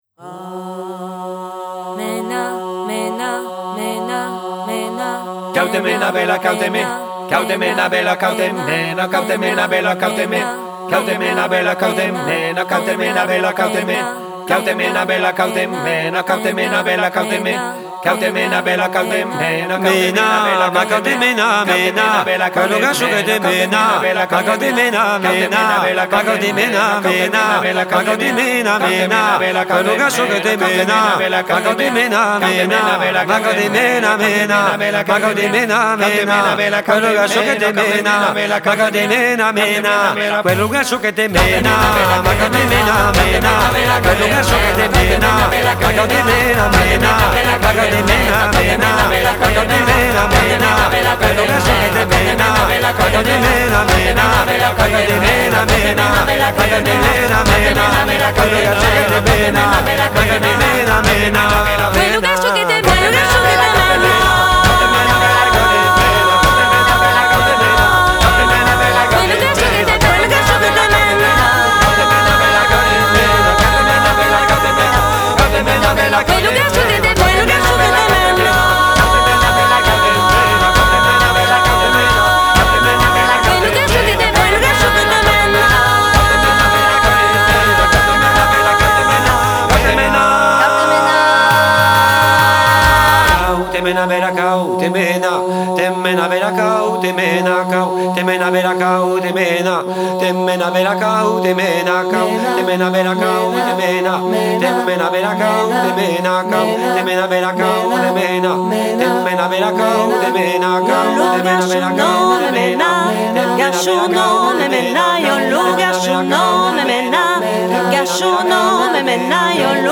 Jazz blues et musique du monde